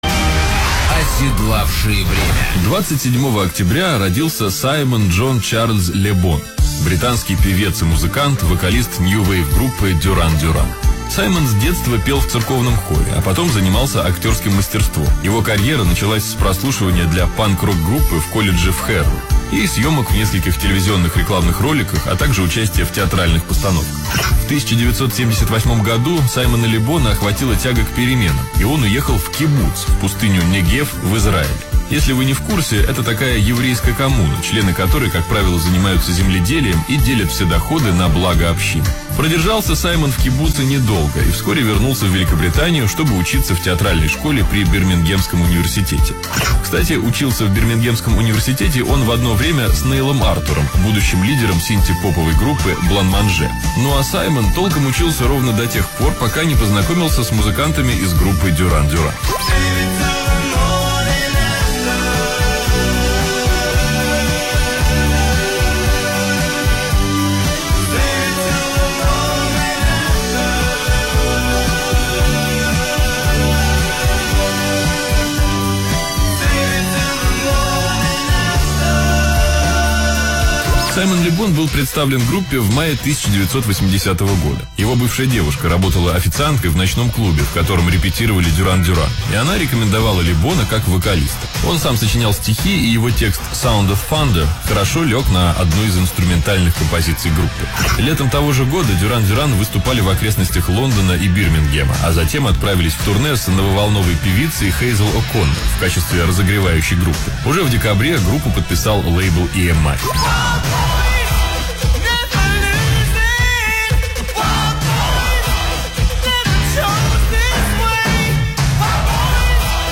Большое спасибо за эту вырезку из эфира!